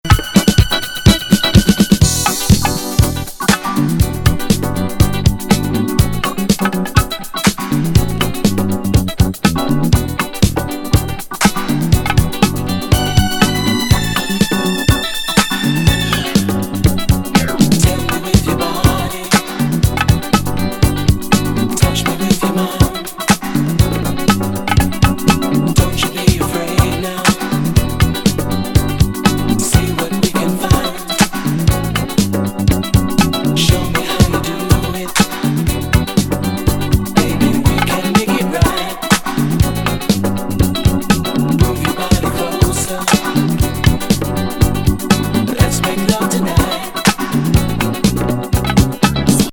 ホワイト・ディスコ・グループ79年1ST。